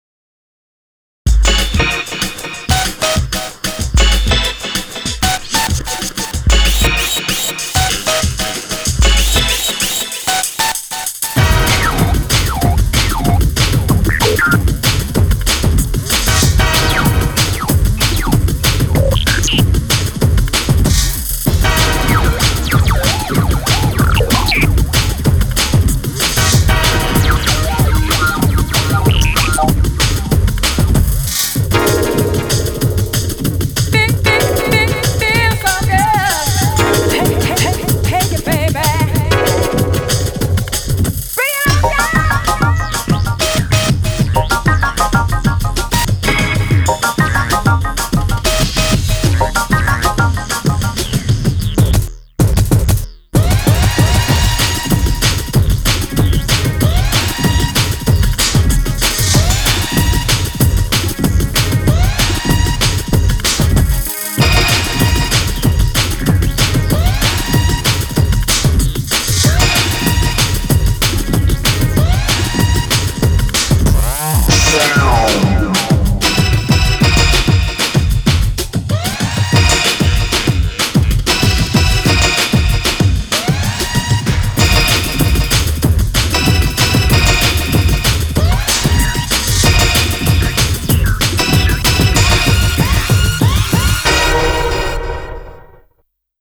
BPM190
Audio QualityPerfect (High Quality)